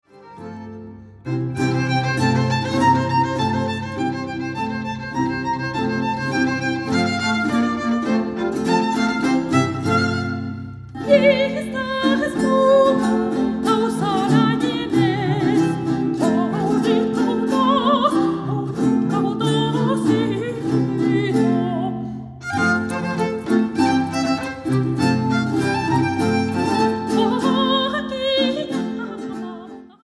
Aria
Dúo
soprano, flauta, percusiones
violín, violín piccolo
guitarra barroca, laúd, mandolina, charango, viola de gamba